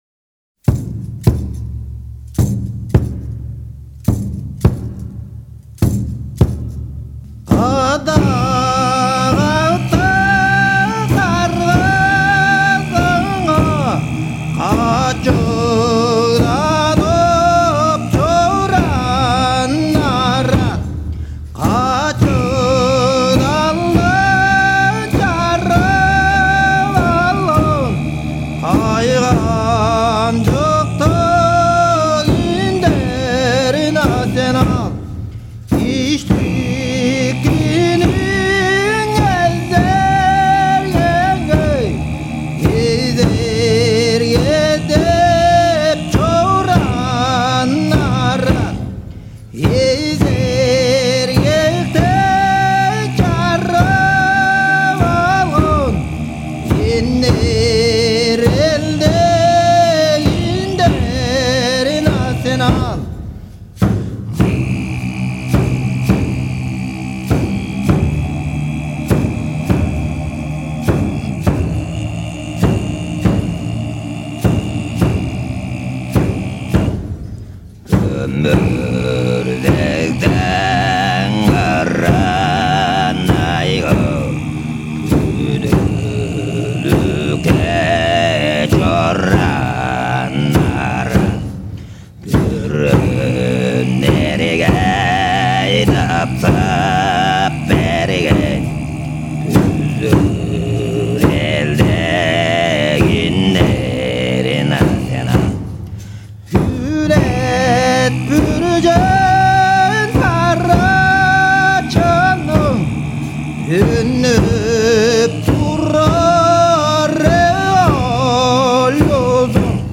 蒙古乐团